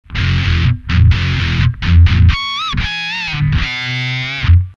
Тут позиция такая - с приведенным в этой же статье спикерсимулятором в линию все зазвучит гораздо приятнее, чем в дешевый комбо (что подтверждают звуки, записанные именно в линию, а не с микрофона).
(38kB) - ламповый преамп, максимальный драйв, bridge
max_bridge.mp3